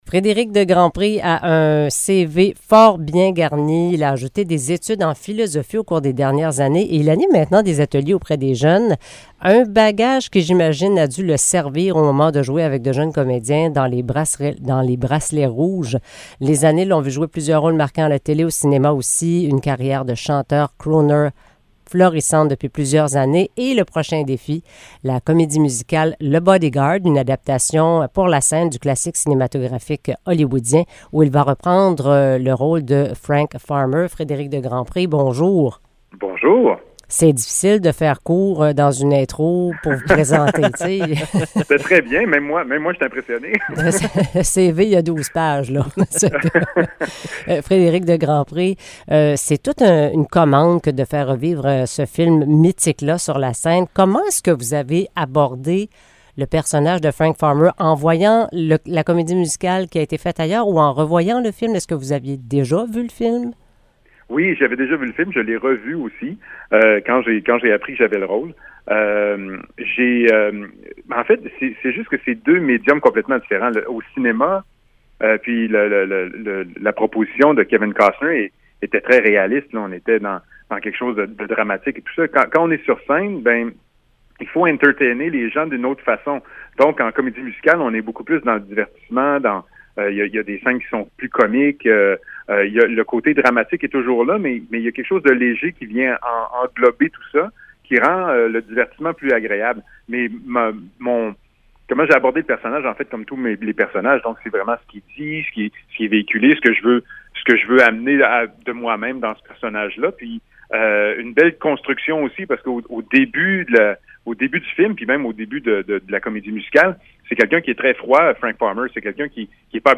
Entrevue avec Frédérick De Grandpré